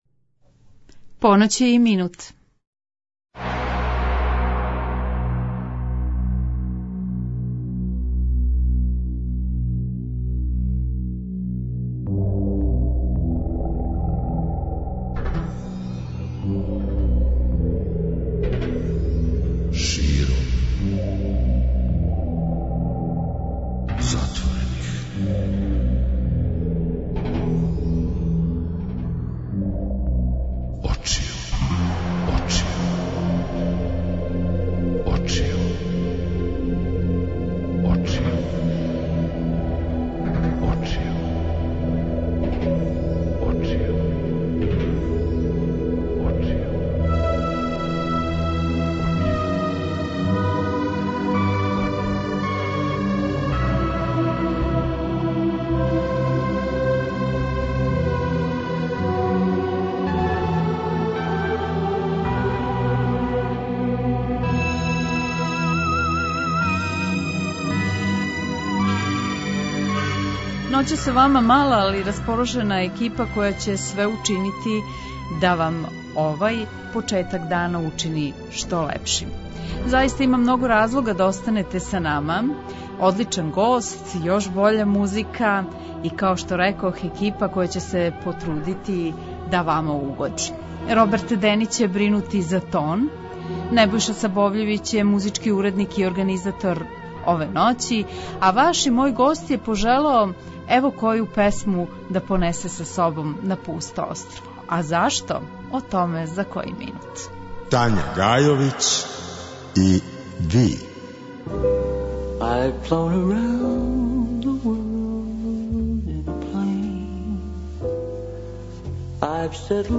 Гост: Корнелије - Бата Ковач, композитор, пијаниста, аранжер